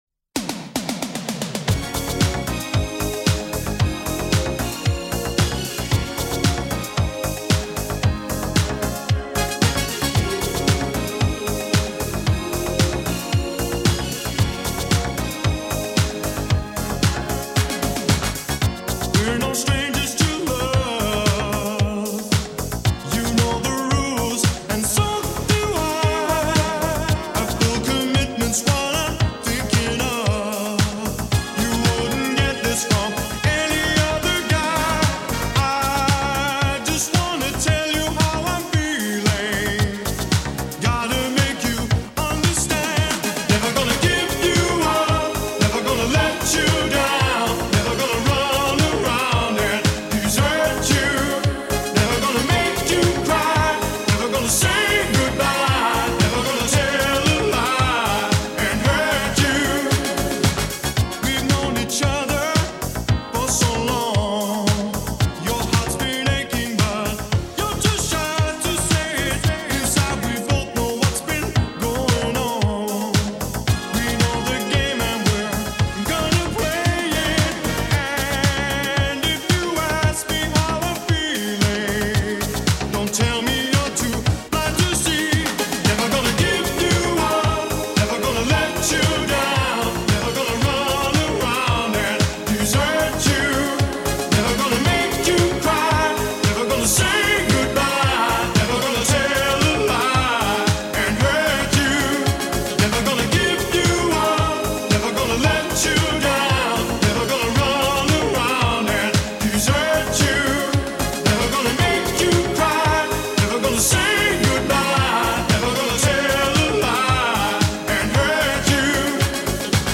Категория: Хиты 80-х